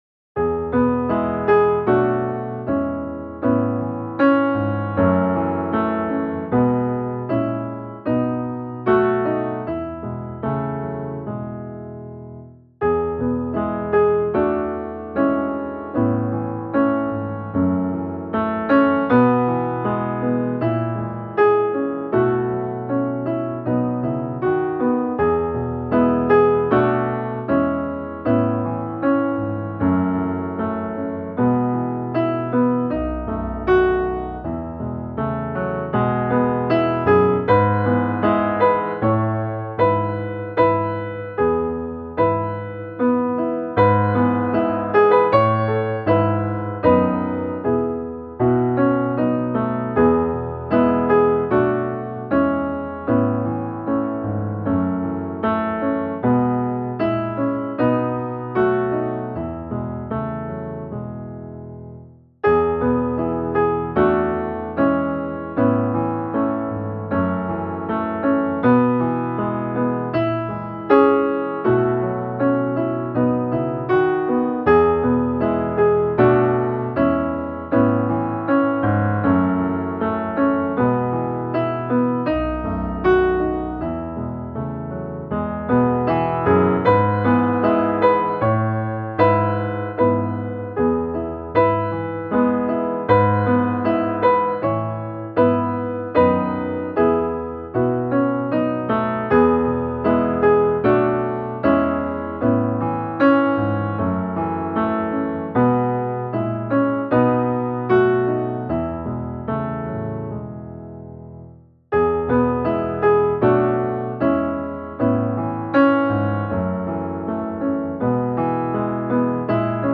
Frälsare, tag min hand - musikbakgrund
Musikbakgrund Psalm